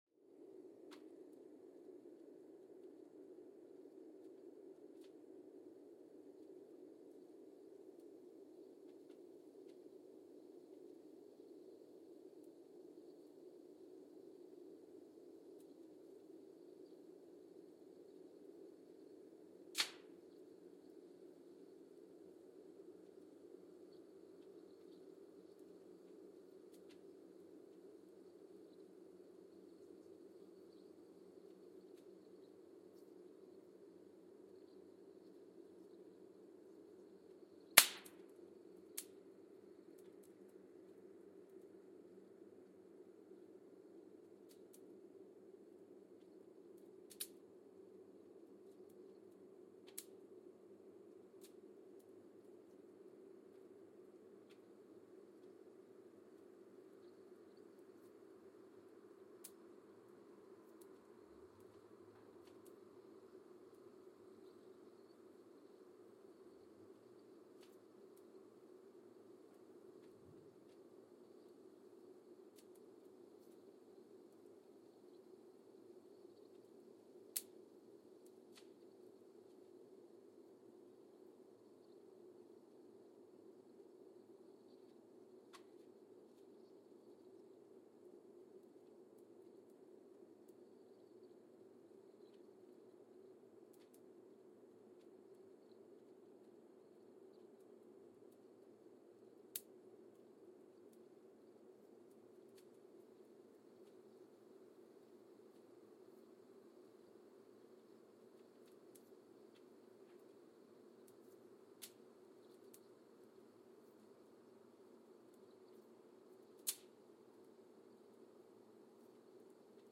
Mbarara, Uganda (seismic) archived on February 23, 2024
Sensor : Geotech KS54000 triaxial broadband borehole seismometer
Recorder : Quanterra Q330 @ 100 Hz
Speedup : ×1,800 (transposed up about 11 octaves)
Loop duration (audio) : 05:36 (stereo)
Gain correction : 20dB
SoX post-processing : highpass -2 90 highpass -2 90